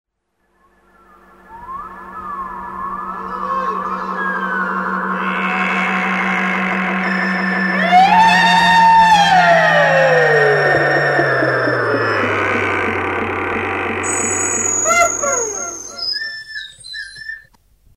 Percussion instruments
Guitar, Voice, Various instruments